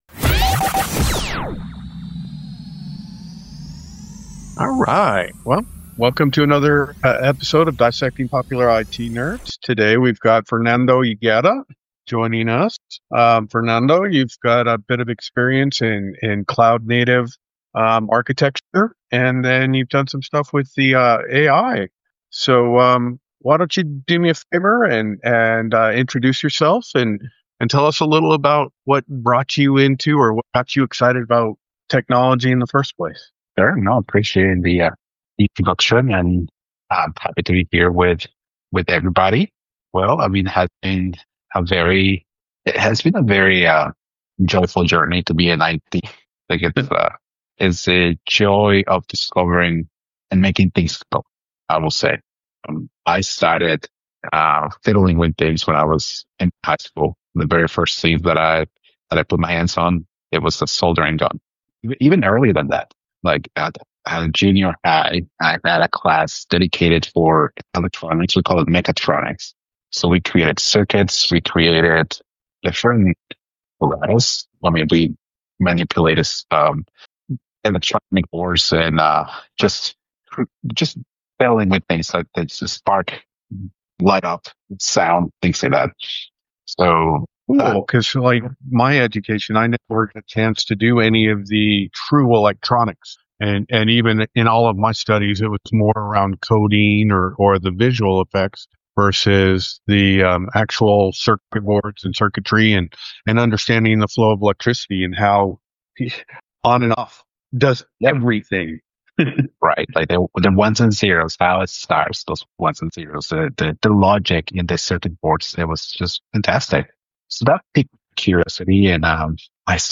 Fascinating conversation